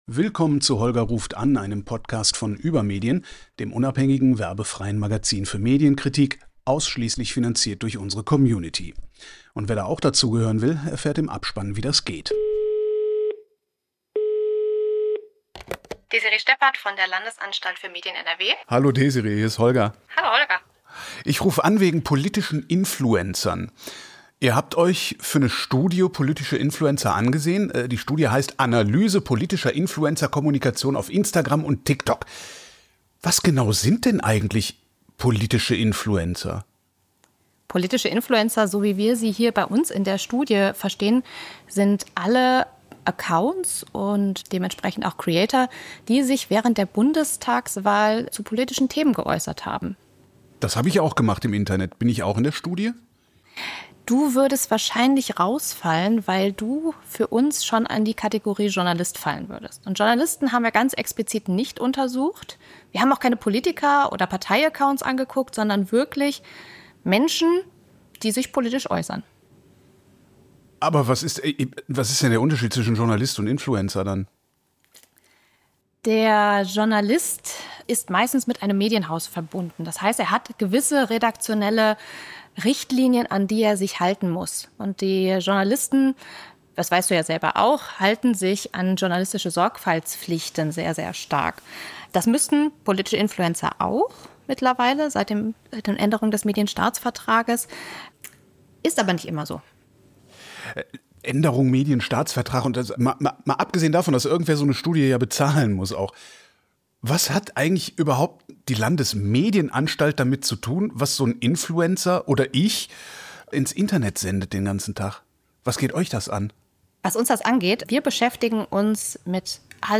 … continue reading 221 ตอน # Übermedien # Gesellschaft # Kino # Medienkritik # Medien # Business # Branchen # News Talk # Nachrichten